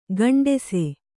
♪ gaṇḍese